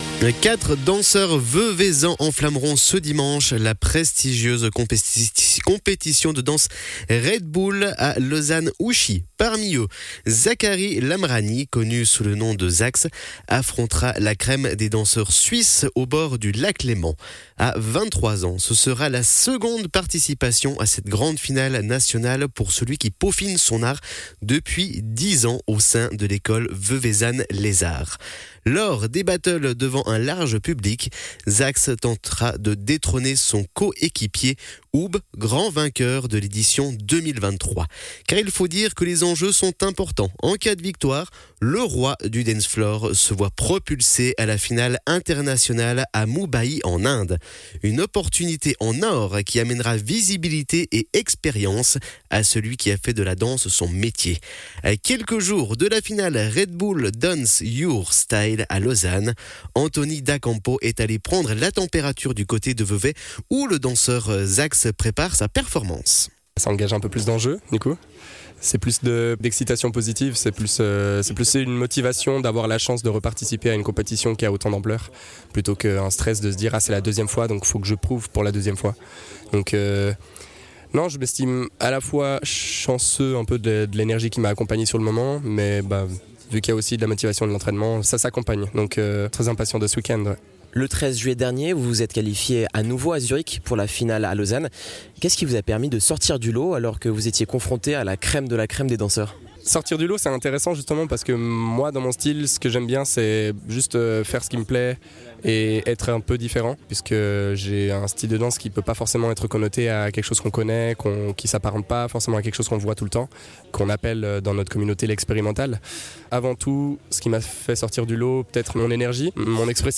danseur professionnel